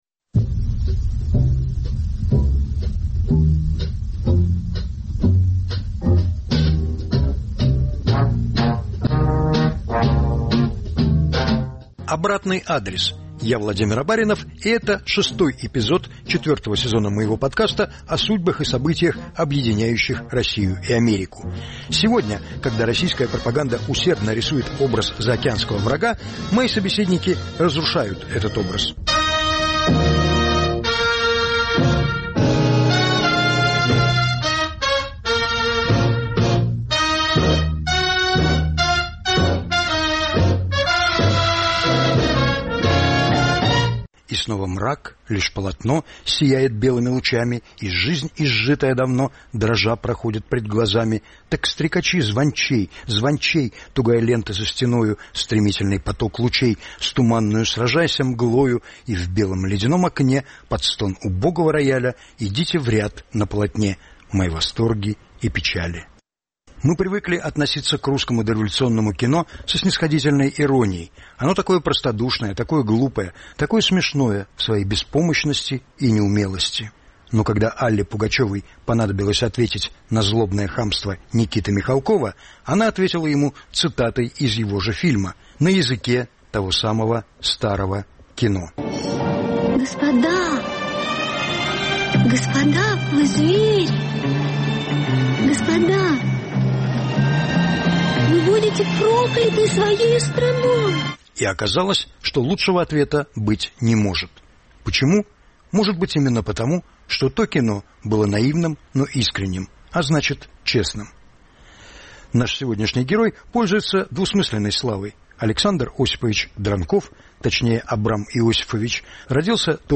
повтор эфира от 21 октября 2022 года.